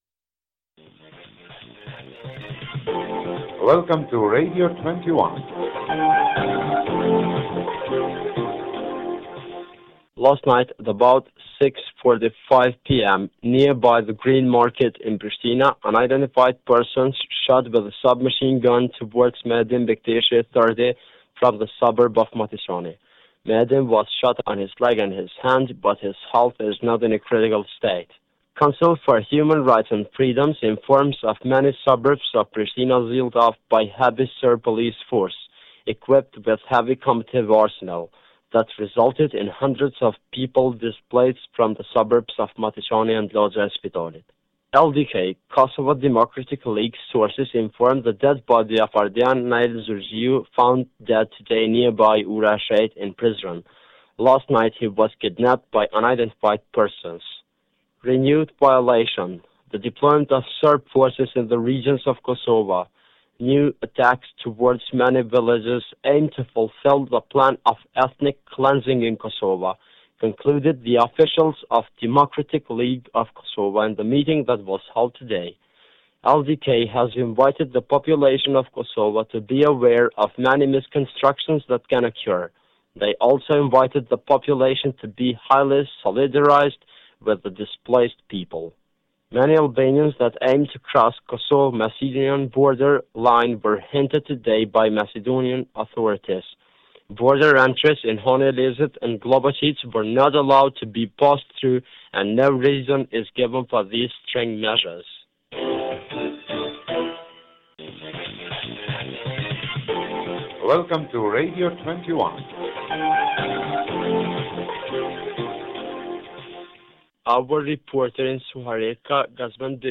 March 22, 1993 – broadcasts from Radio 21, Kosovo